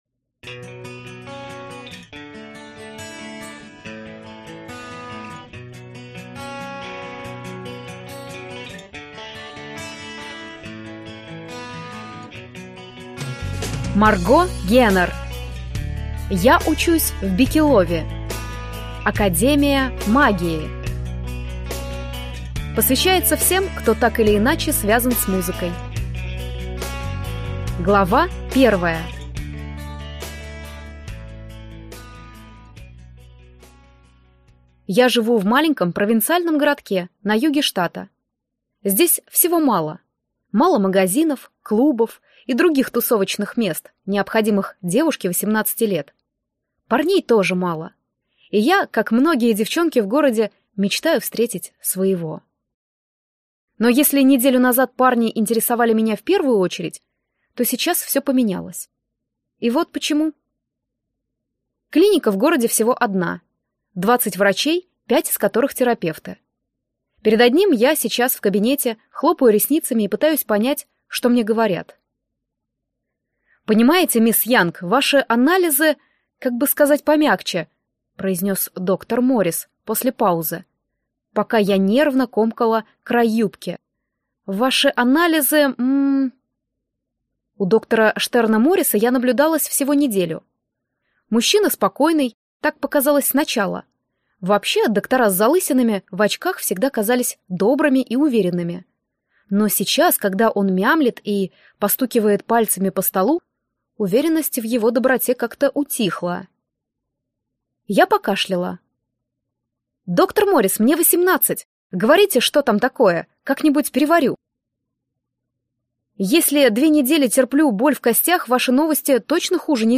Аудиокнига Я учусь в Бикелови! Академия магии | Библиотека аудиокниг